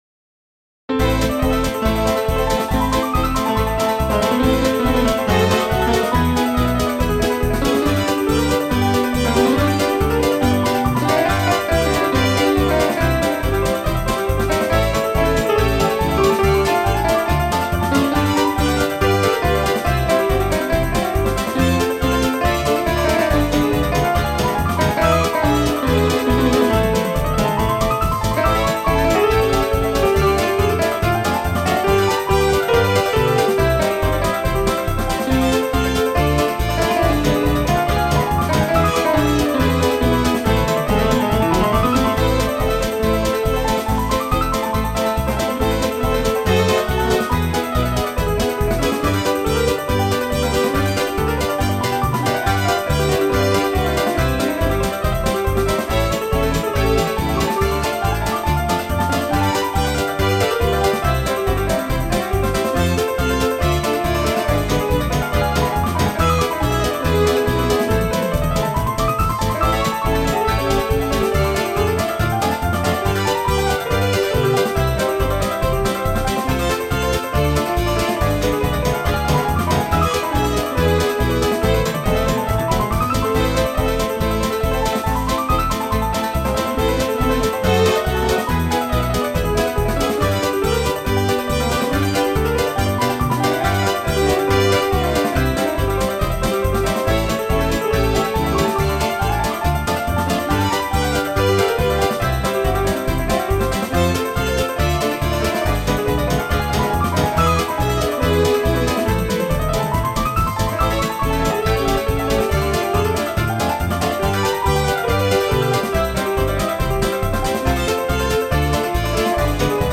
7/  Rómske pesničky
upravené pre hru na viac nástrojov